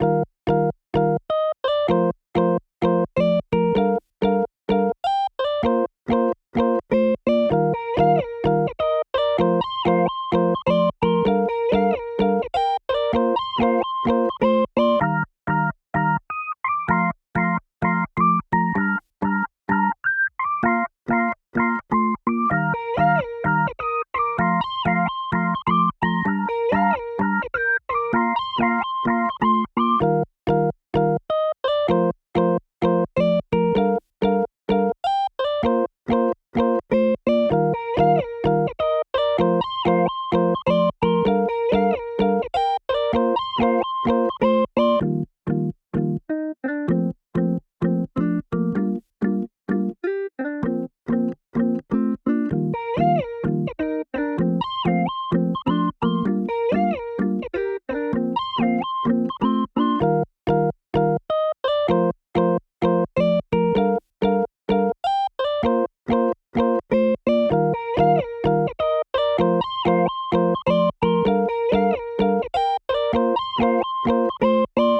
2. Hip Hop Instrumentals